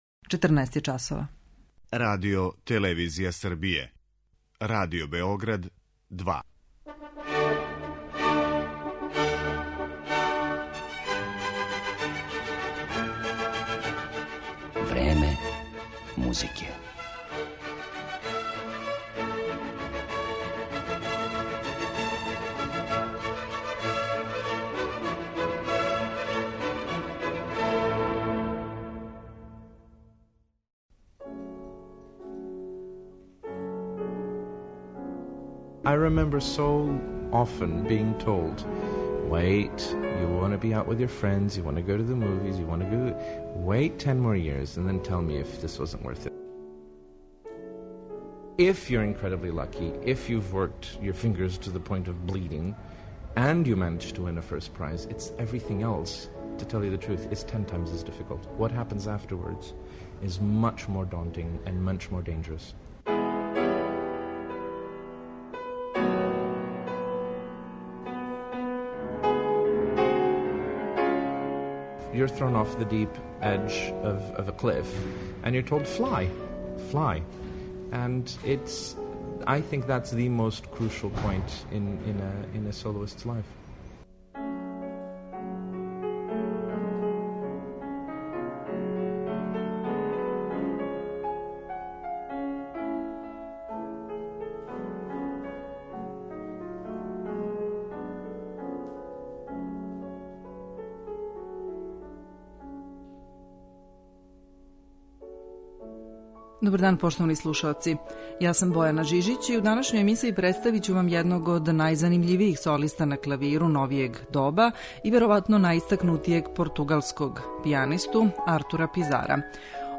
Данашња емисија је посвећена једном од најзанимљивијих солиста на клавиру, најистакнутијем португалском пијанисти Артуру Пизару.